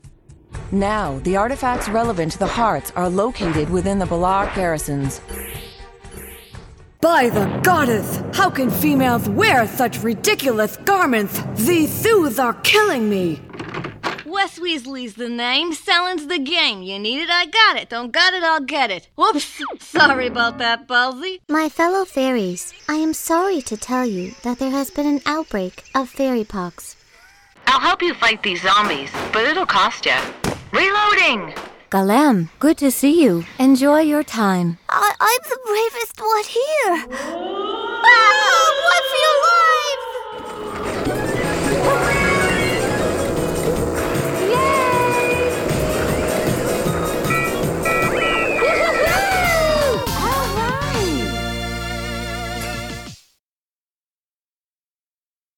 My voice has been described as friendly, youthful, wholesome, and real. But I can sound tough when I need to! Vocal age range teens-30s.
Sprechprobe: Sonstiges (Muttersprache):